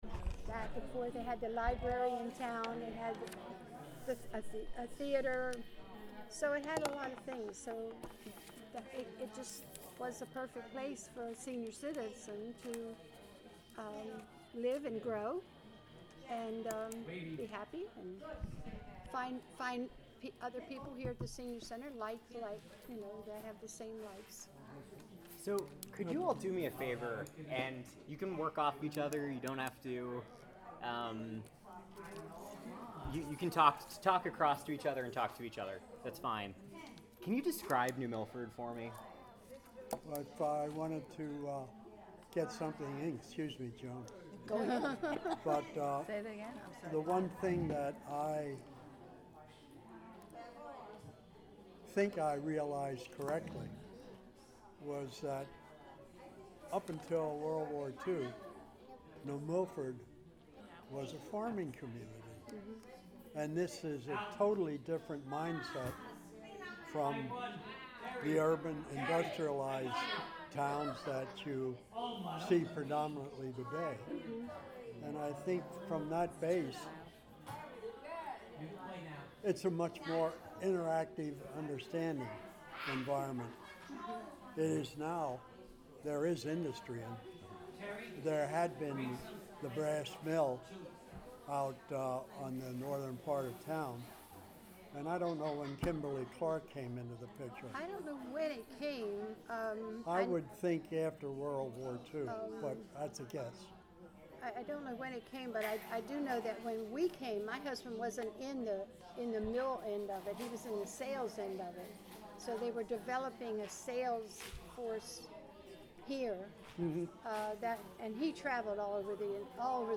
Oral History
Location Burnham Library, Bridgewater, CT